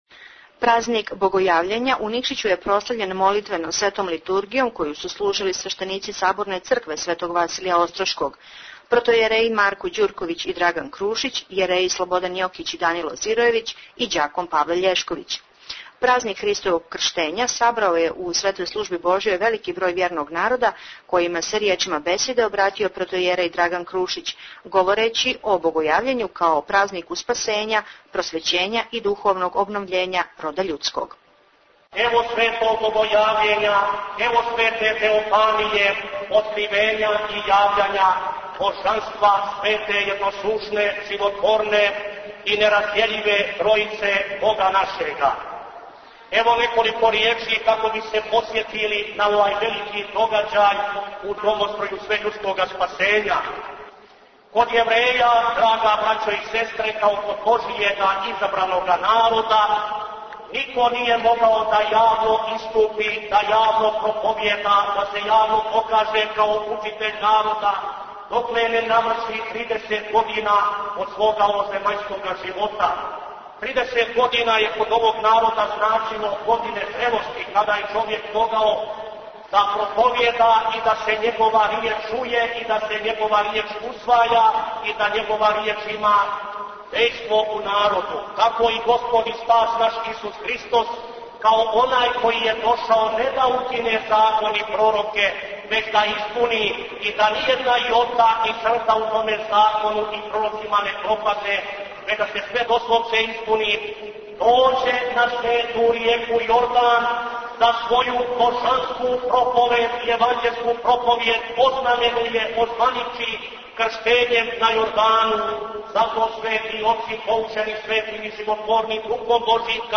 Празник Богојављење прослављен Светом Литургијом која је служена у Саборној цркви Светог Василија Острошког у Никшићу. Прилог